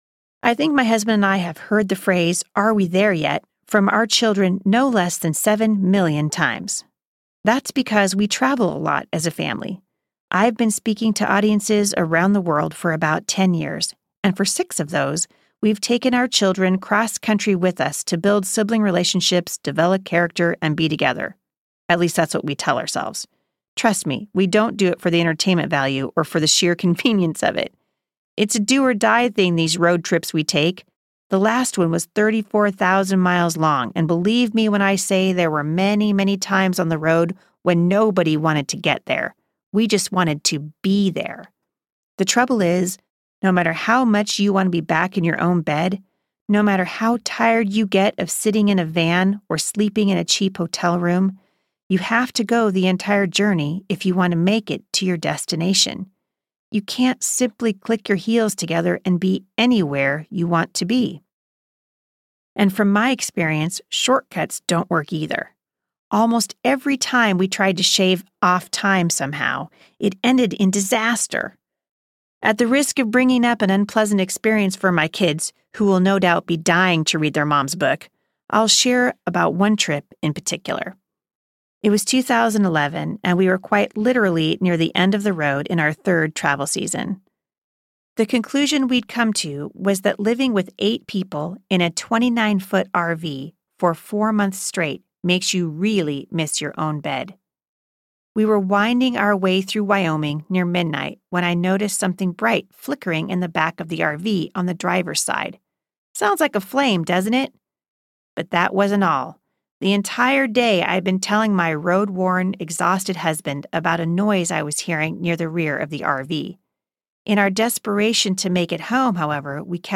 Becoming MomStrong Audiobook
6.78 Hrs. – Unabridged